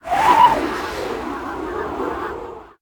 风.ogg